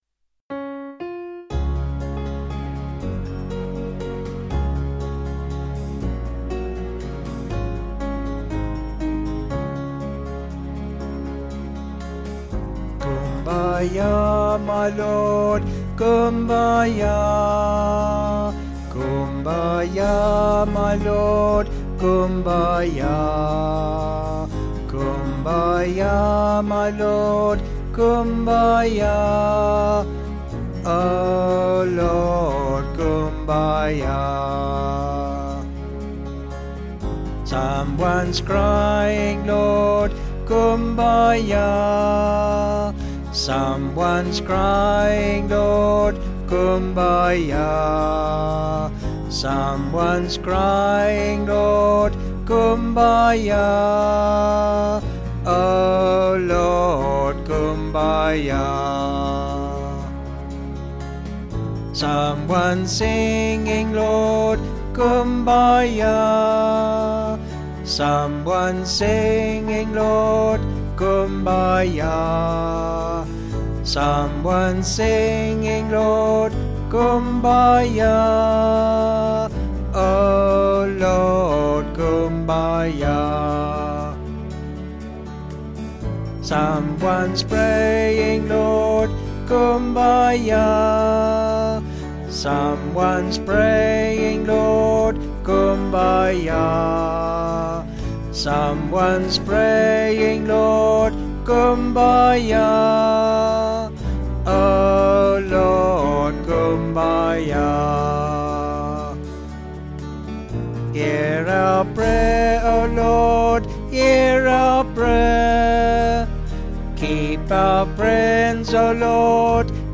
Vocals and Band   706.7kb